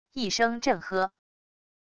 一声震喝wav音频